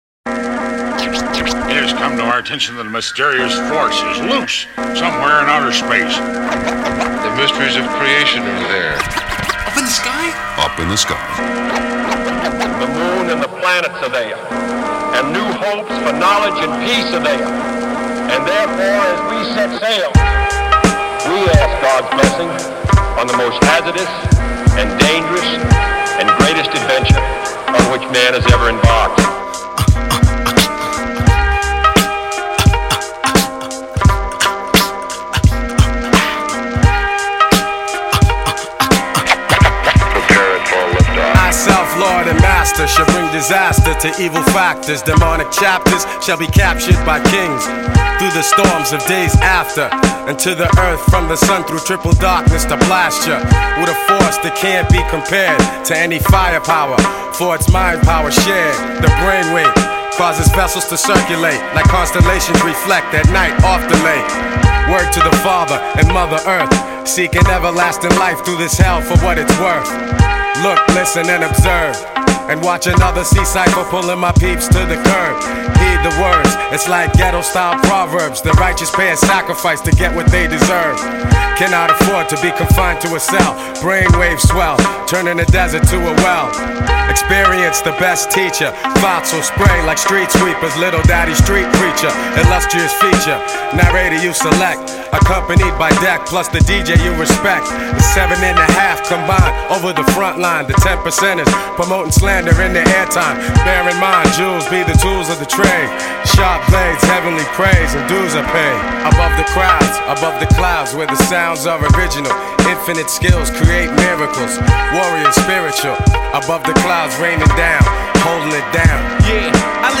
30 Nov 2022 Not many rap tunes start with a JFK speech.